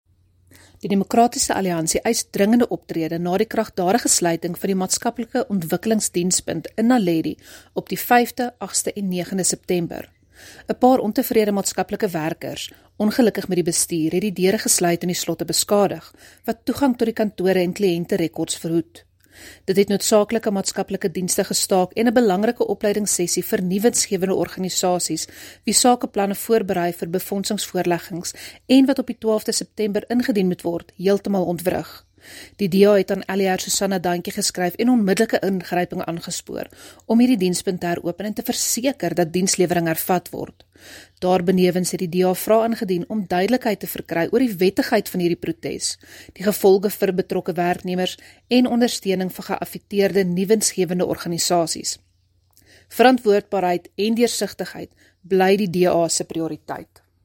Note to Broadcasters: Please find linked soundbites in English and